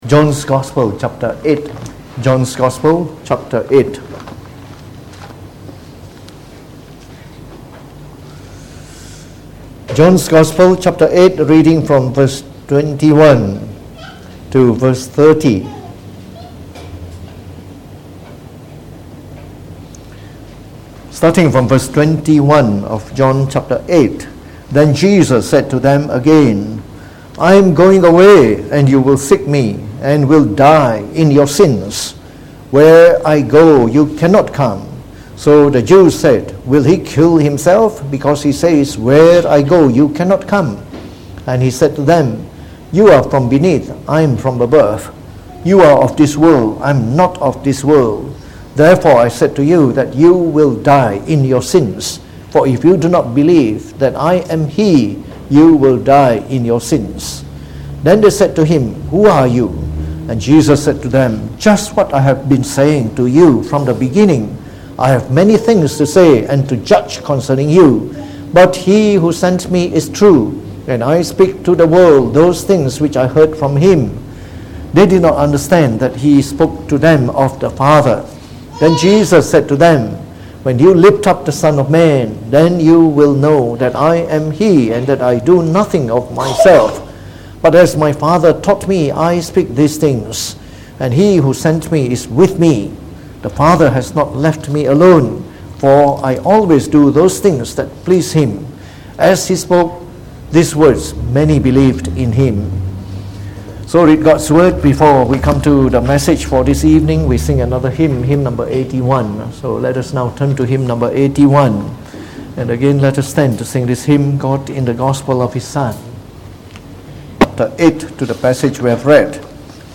Preached on the 16th December 2018.